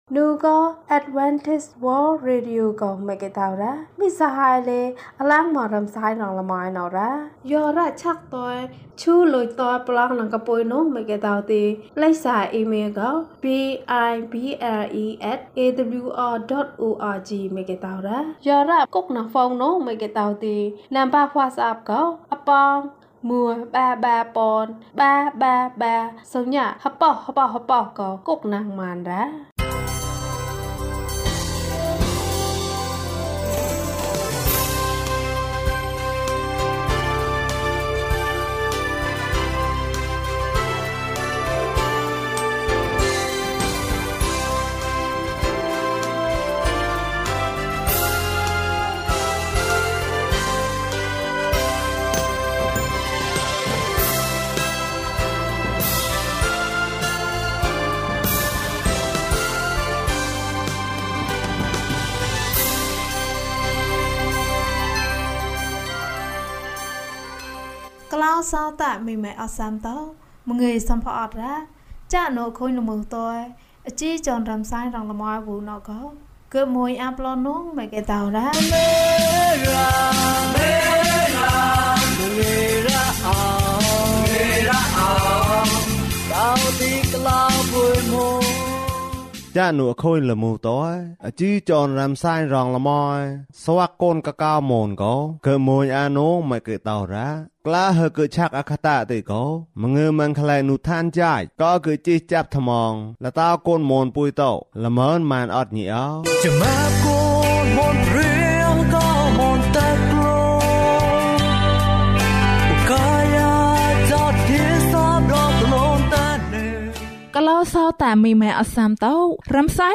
ခရစ်တော်ထံသို့ ခြေလှမ်း။၅၇ ကျန်းမာခြင်းအကြောင်းအရာ။ ဓမ္မသီချင်း။ တရားဒေသနာ။